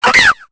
Cri de Furaiglon dans Pokémon Épée et Bouclier.